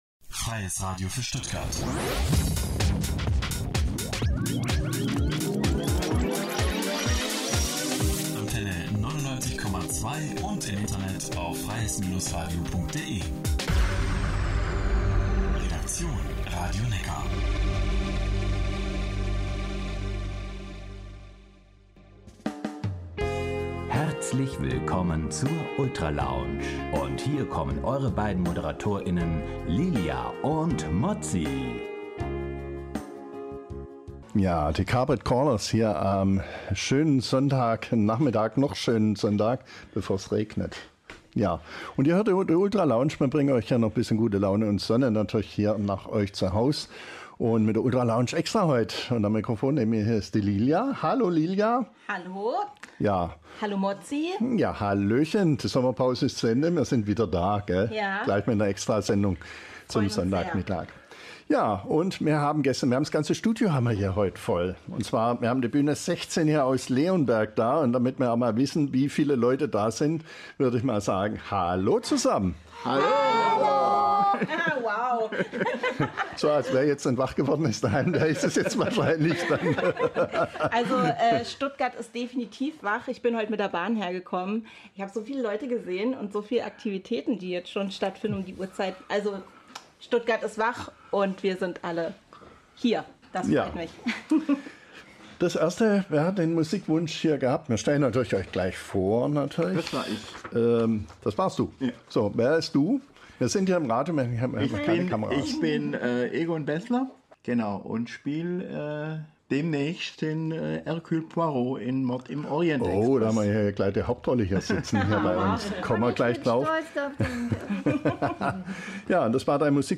In der einstündigen Sendung „Ultra-Lounge“ waren Ensemblemitglieder der bühne 16 aus Leonberg zu Gast.
Hier kann man die Sendung nachhören, aus rechtlichen Gründen leider ohne Musiktitel: